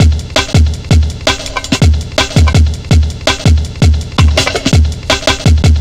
Index of /90_sSampleCDs/Zero-G - Total Drum Bass/Drumloops - 3/track 44 (165bpm)